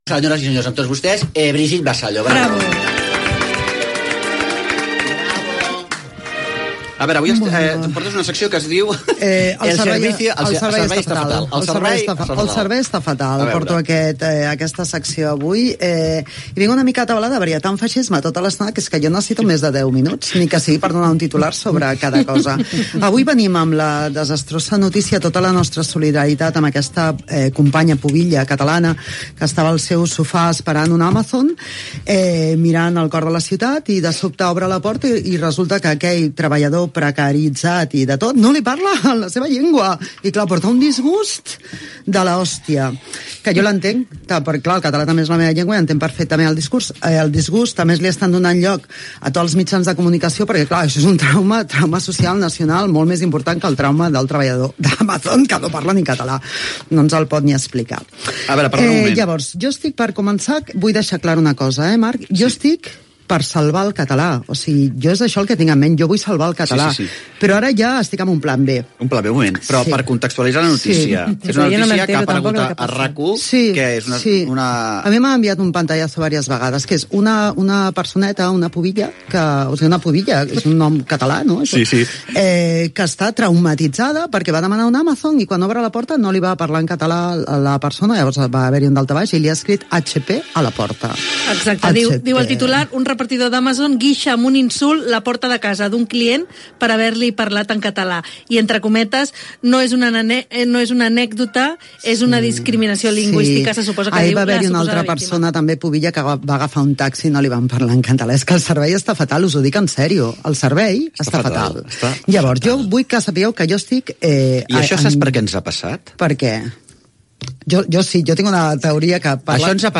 Comentari sobre l'ús del català a la societat del moment
Entreteniment